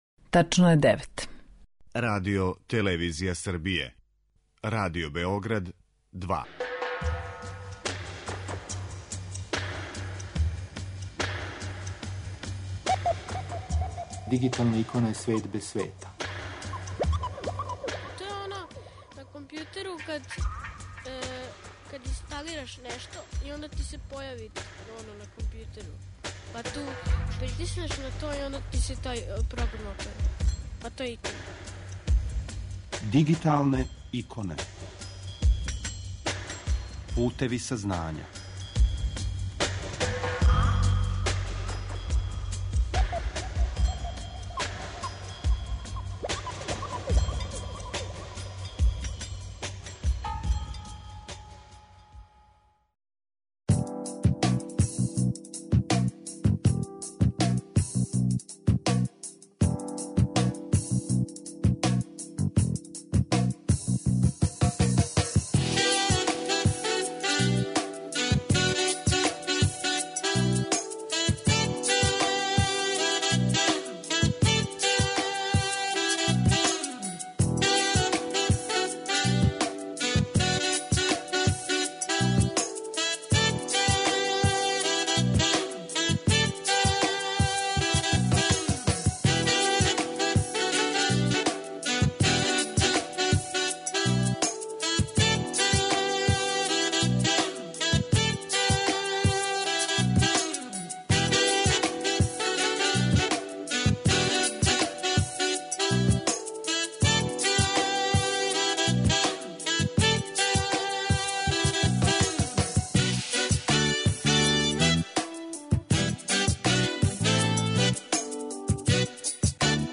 Са нама уживо: